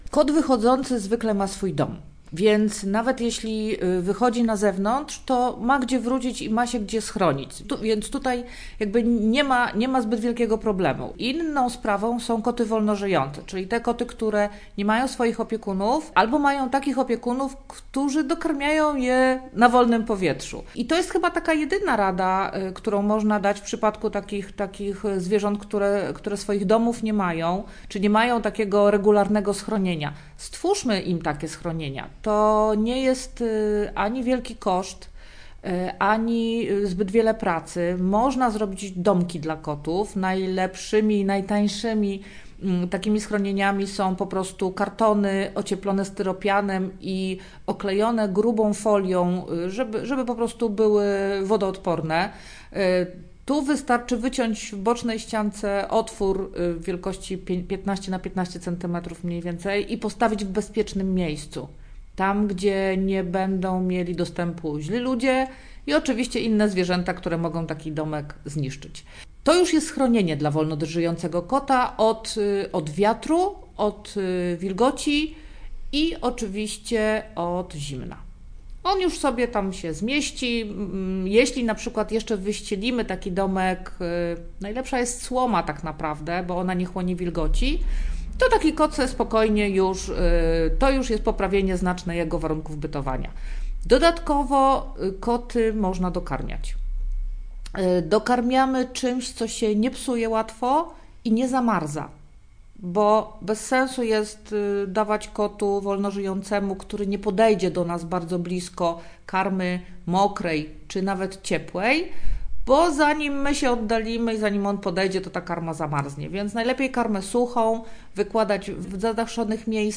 Podpowiada koci behawiorysta
0118_wywiad_koci_behawiorysta.mp3